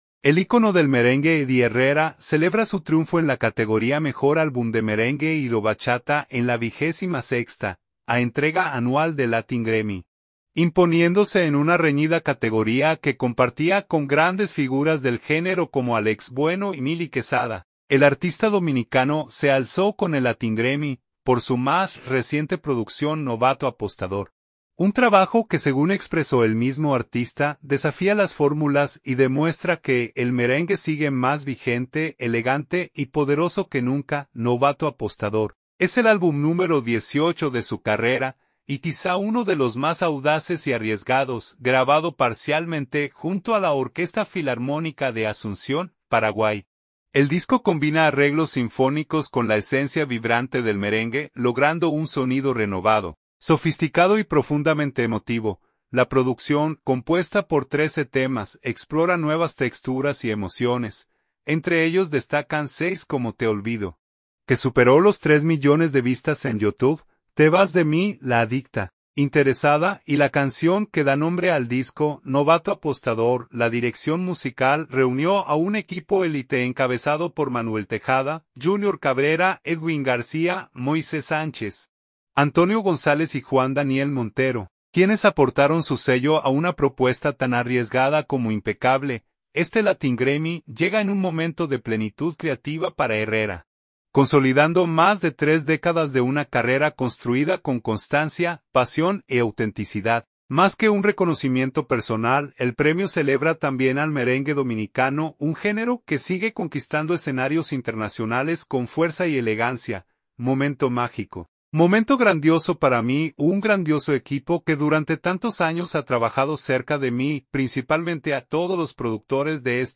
merengue